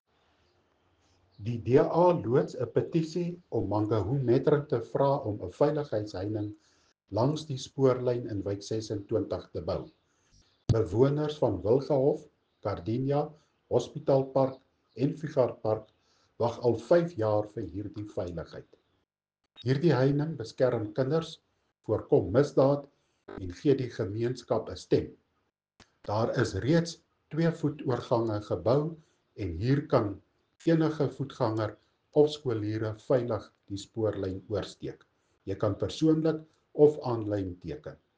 Afrikaans soundbites by Cllr Hennie van Niekerk and